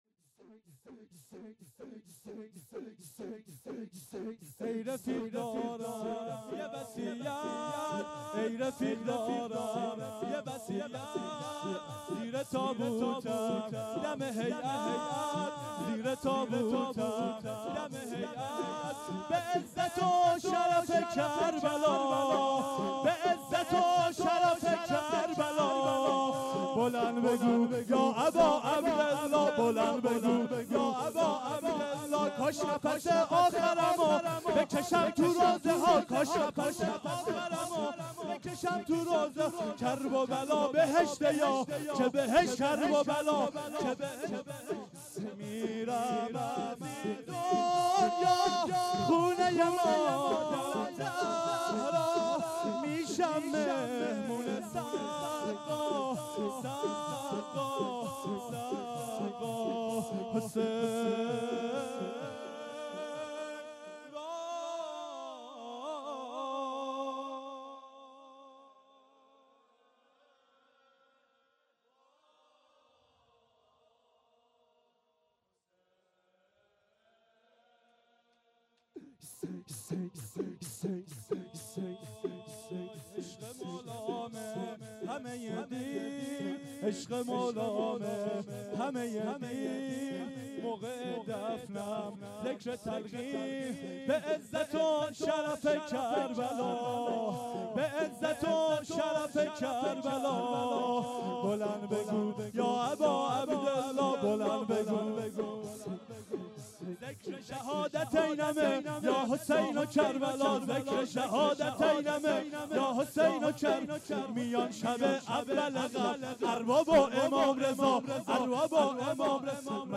شور 2